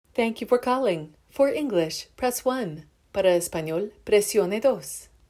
Feel free to copy and paste the standard text below, or download a pre-recorded greeting
IVR-Eng-Spa-Generic.mp3